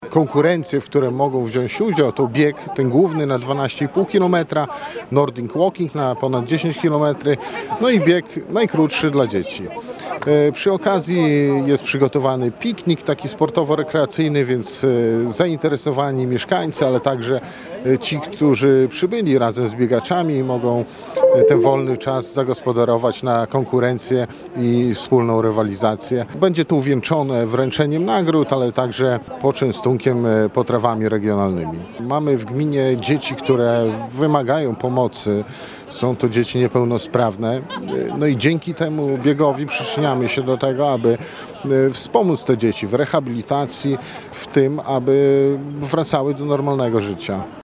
– Bawimy się, promujemy zdrowy tryb życia, a jednocześnie pomagamy – mówił w rozmowie z Radiem 5 Karol Szrajbert, wójt gminy Krasnopol.